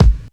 Kick
Original creative-commons licensed sounds for DJ's and music producers, recorded with high quality studio microphones.
mid-heavy-bass-drum-sound-g-sharp-key-899-2r7.wav